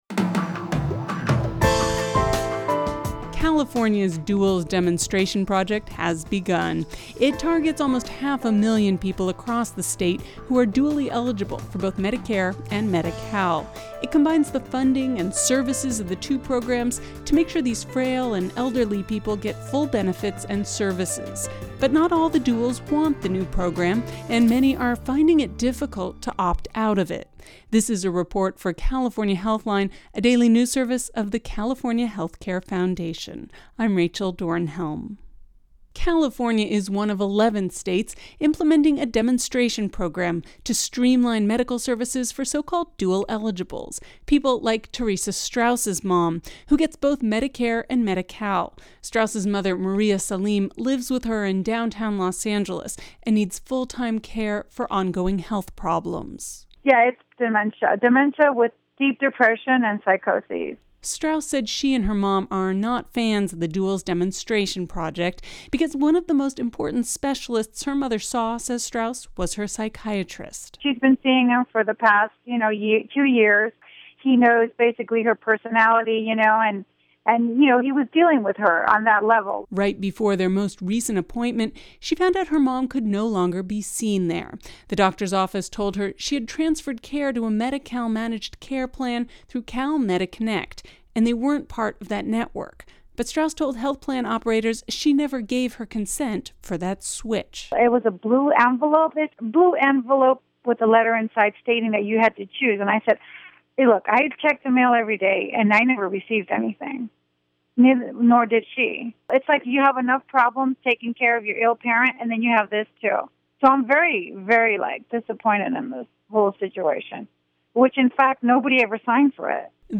The report includes comments from:
Audio Report Insight Medi-Cal Multimedia